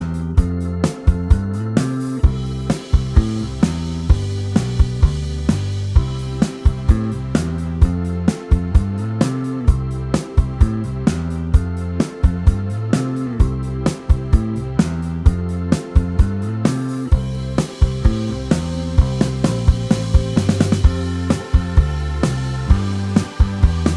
Minus All Guitars Pop (1980s) 2:53 Buy £1.50